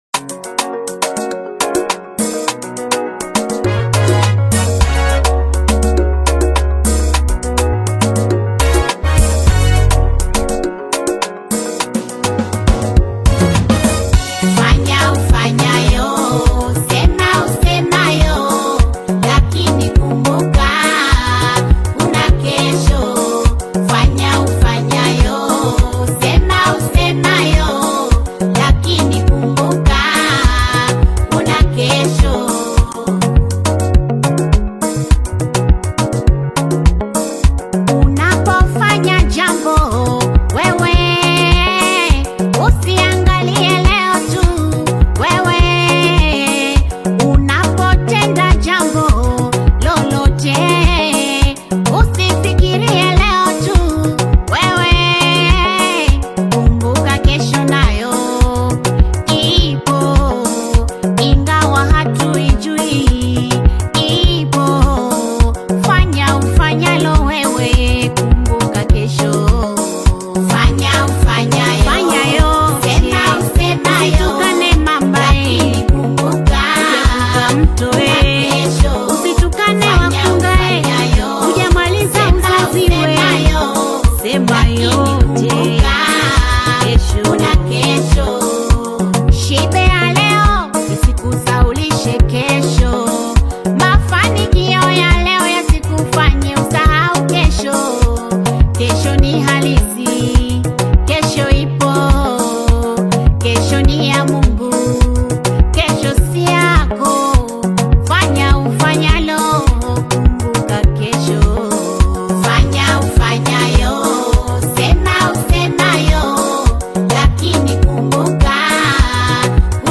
AudioGospel
inspirational gospel single
powerful spiritual vocal delivery and reflective lyricism